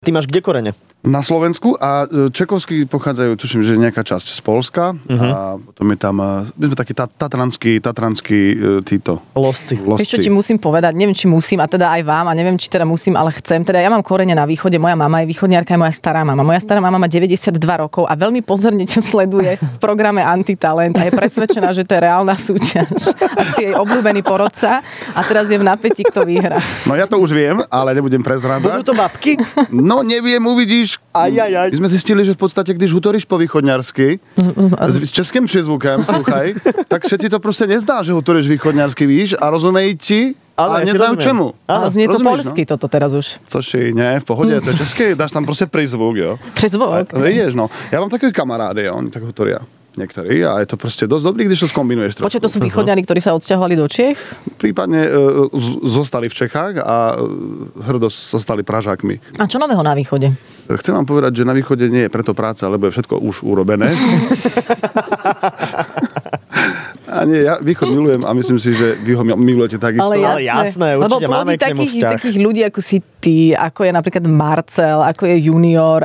Klavírová telenovela v Rannej šou